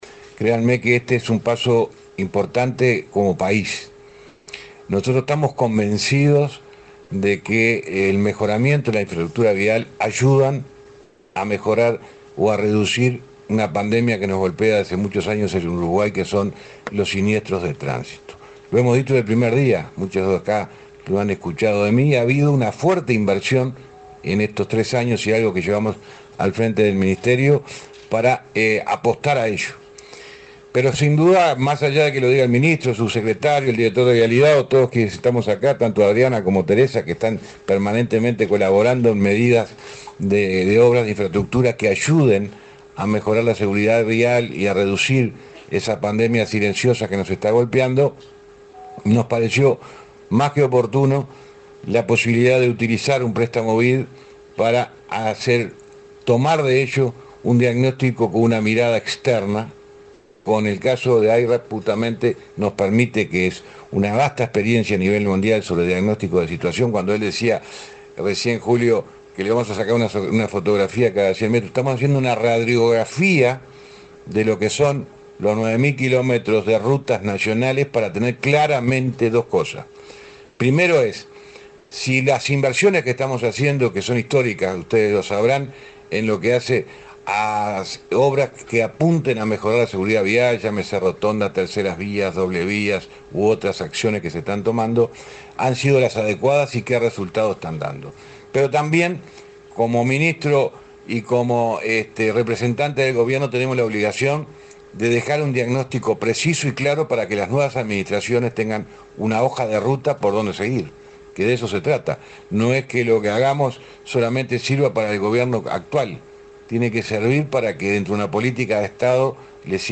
Palabras del ministro de Transporte, José Luis Falero
Palabras del ministro de Transporte, José Luis Falero 13/08/2024 Compartir Facebook X Copiar enlace WhatsApp LinkedIn El Ministerio de Transporte y Obras Públicas realizó, este martes 13 en la sede de esa secretaría de Estado, el lanzamiento de un proyecto de evaluación de la red vial nacional mediante la metodología del Programa Internacional de Evaluación de Carreteras. El titular de la referida cartera, José Luis Falero, explicó el alcance del plan.